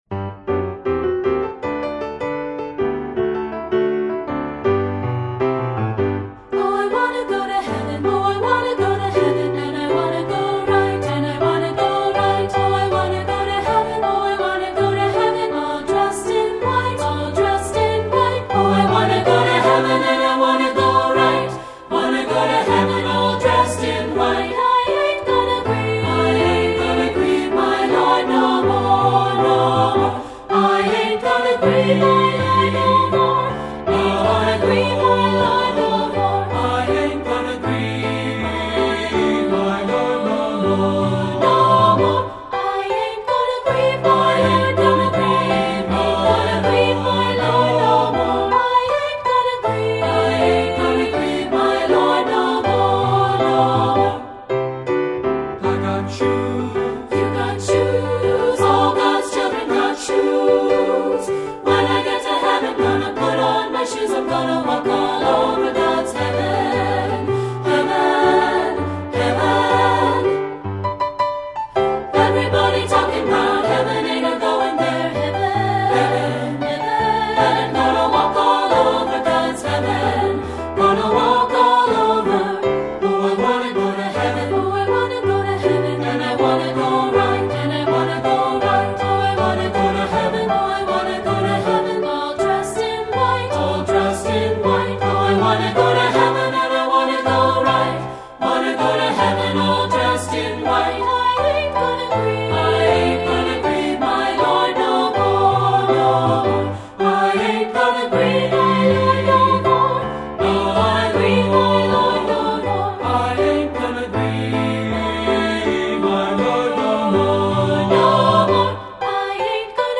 Voicing: 3-Part Mixed and Piano